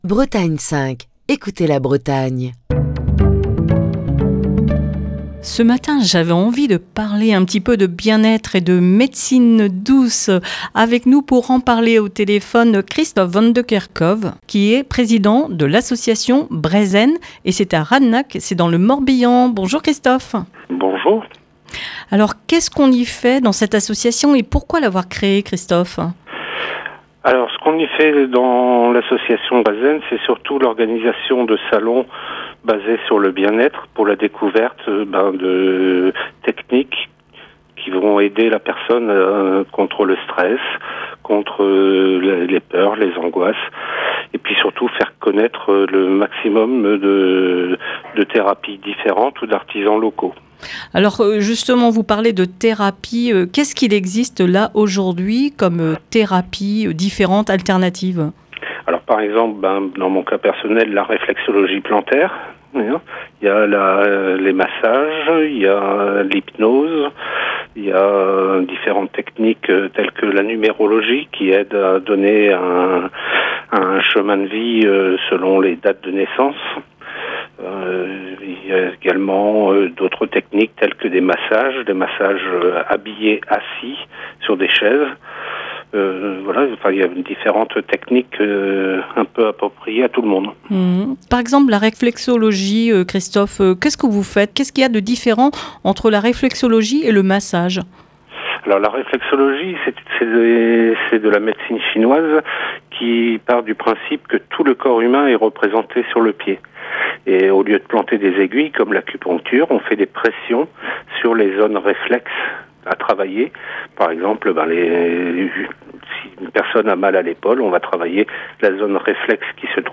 Émission du 9 septembre 2021.